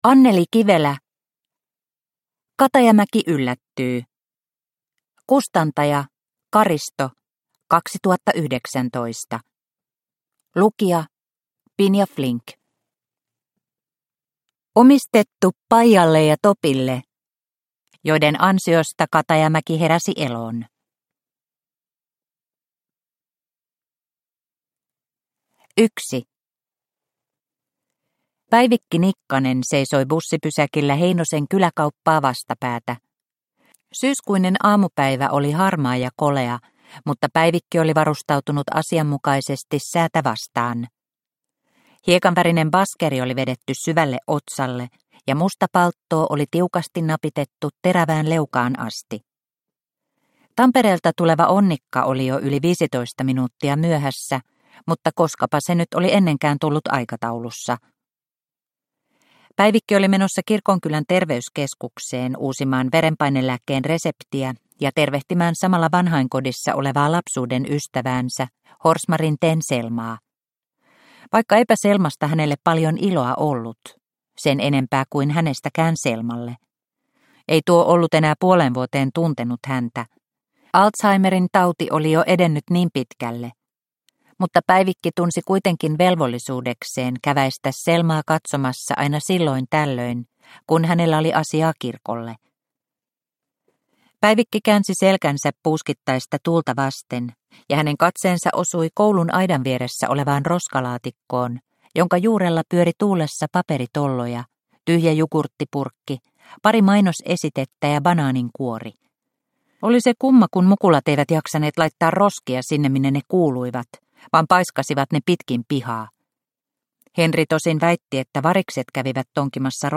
Katajamäki yllättyy – Ljudbok – Laddas ner